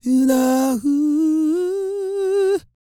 E-CROON 3022.wav